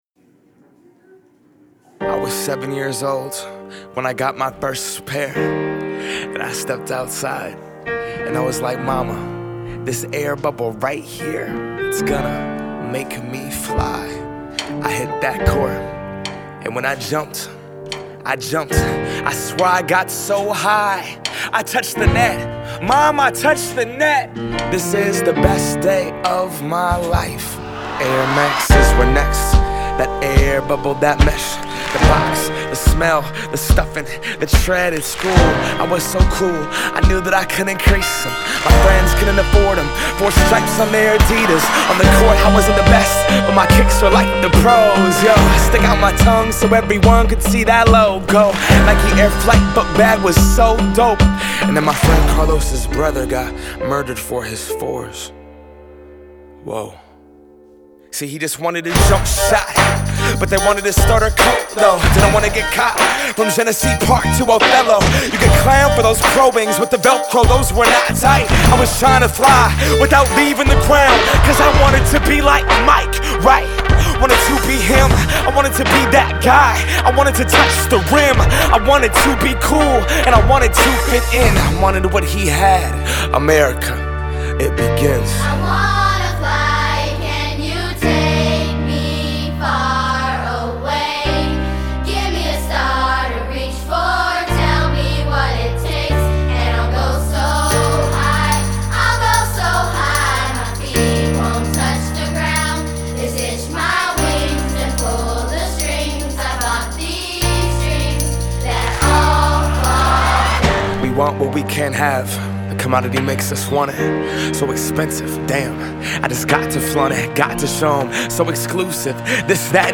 So here it is: a compelling ballad to lost innocence.